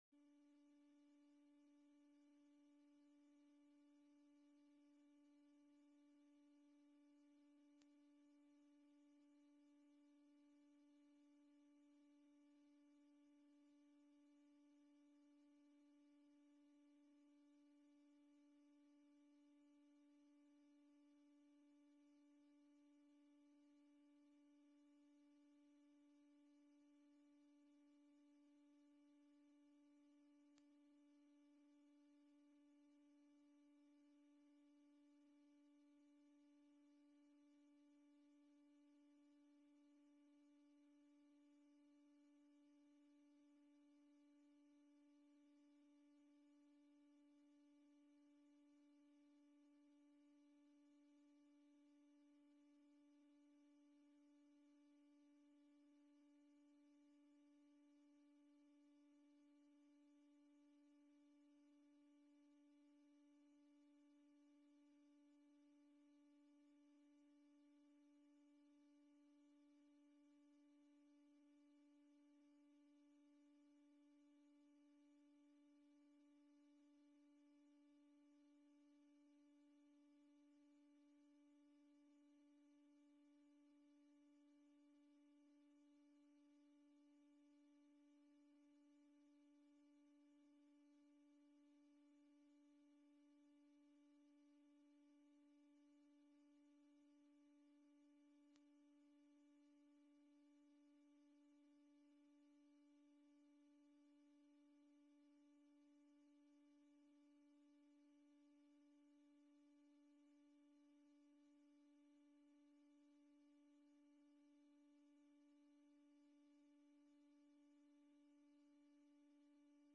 Locatie Online en via livestream Voorzitter Tom Verhoeve